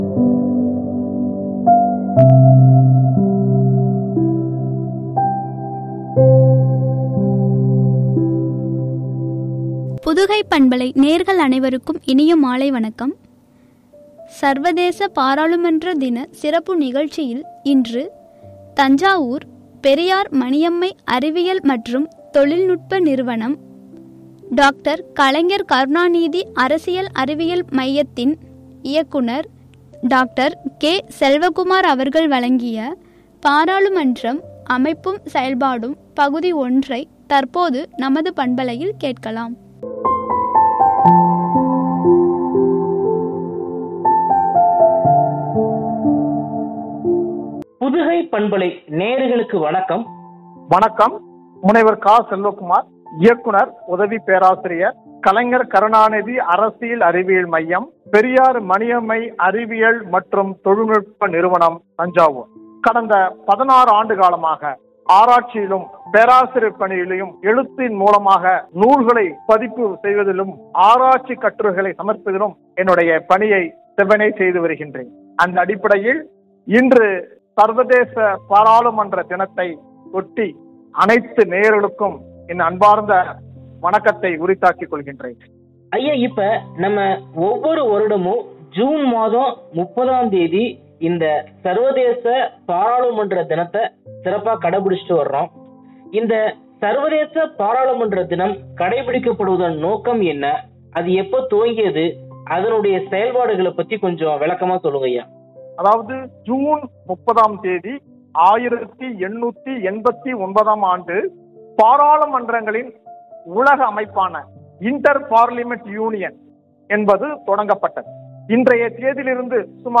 என்ற தலைப்பில் வழங்கிய உரையாடல்.